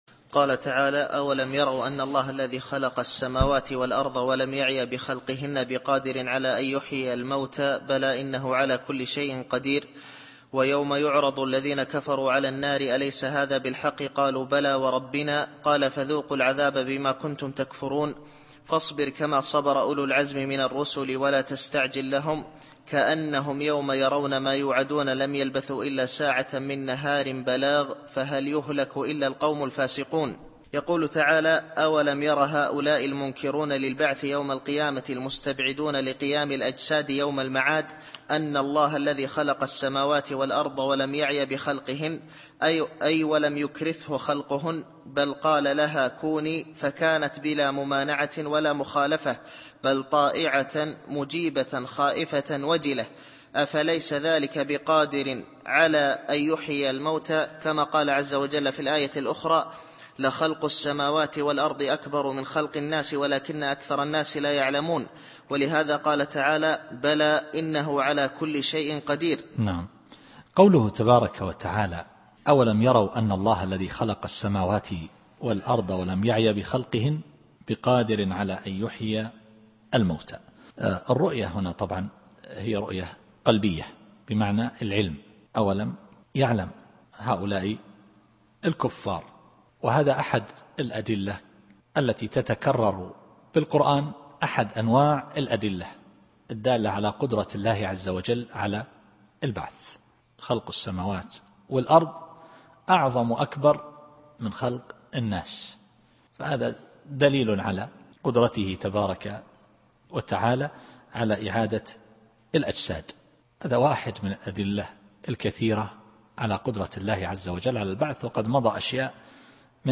التفسير الصوتي [الأحقاف / 33]